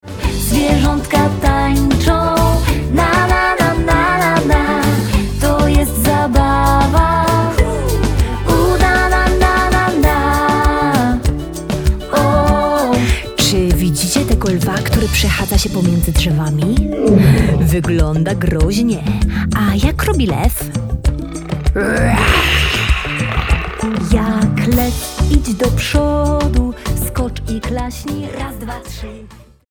Piosenka ruchowa dla dzieci